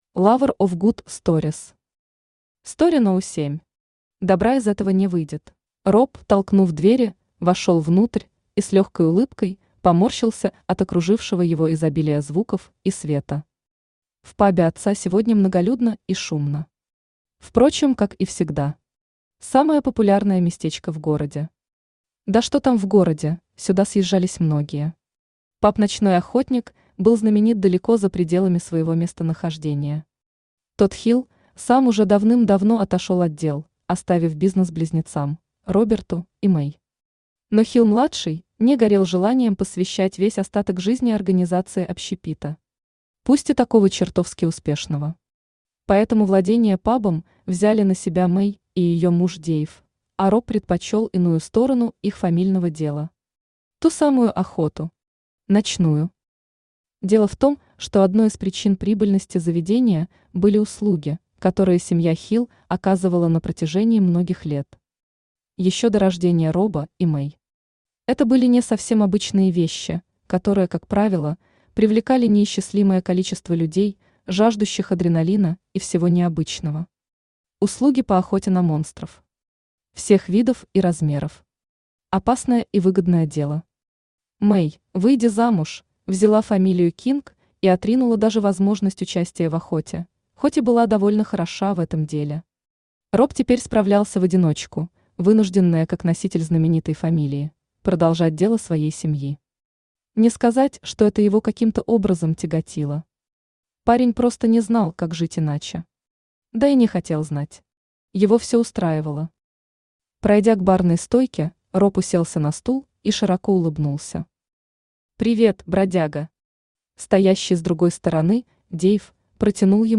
Добра из этого не выйдет Автор Lover of good stories Читает аудиокнигу Авточтец ЛитРес.